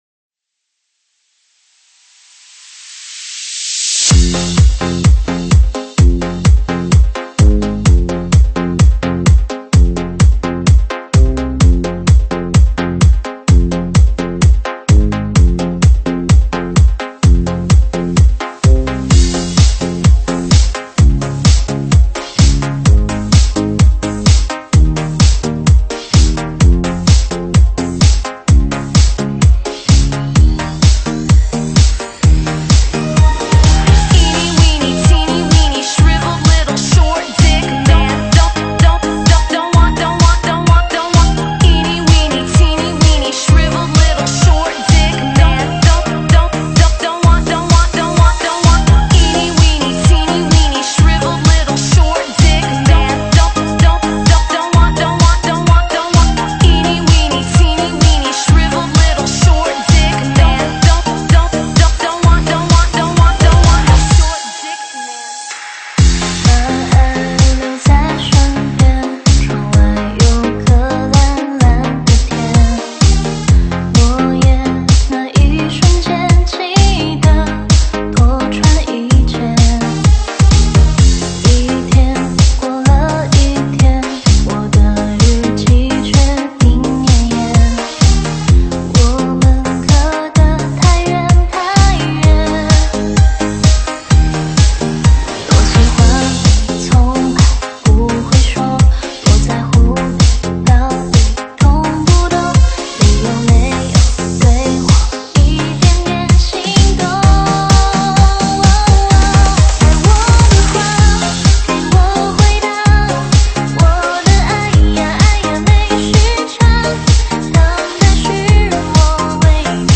舞曲类别：中文Club